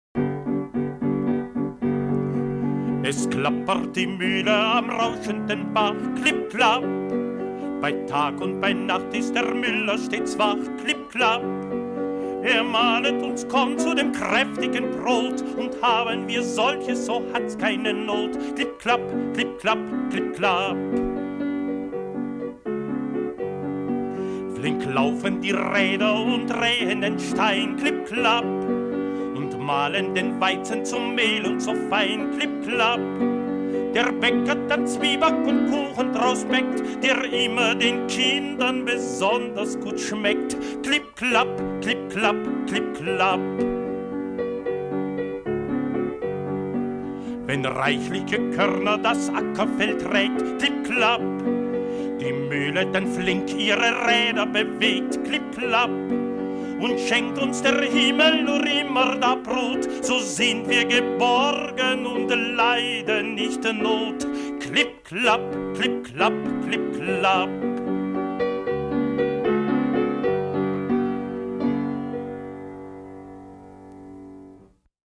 klassisches deutsches Kinderlied